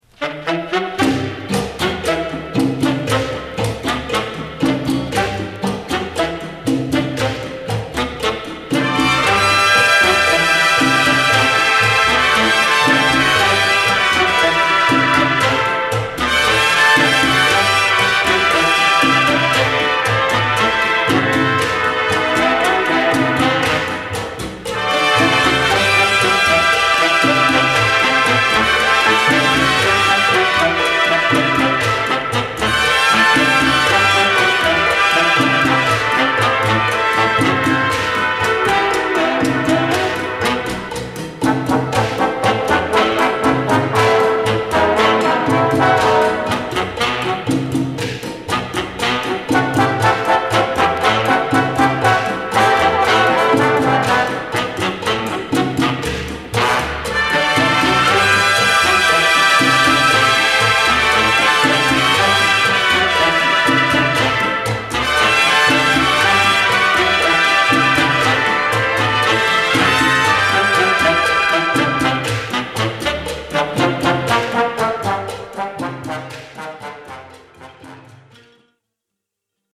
チャチャのリズムで南国感漂うナイスアルバムです。